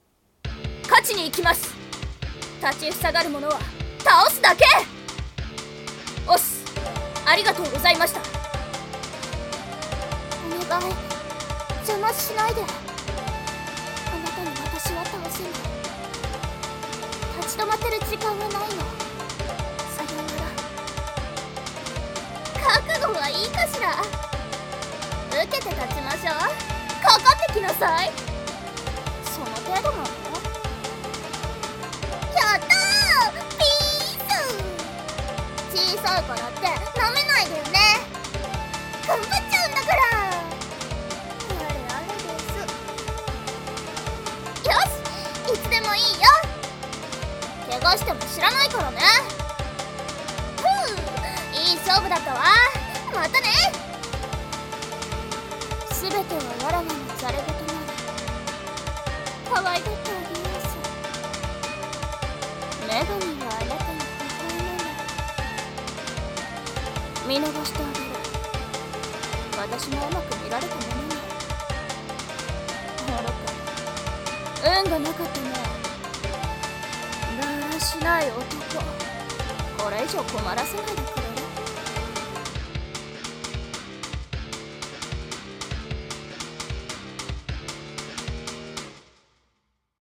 【演じ分け】『格闘ゲーム風台詞集】女ver.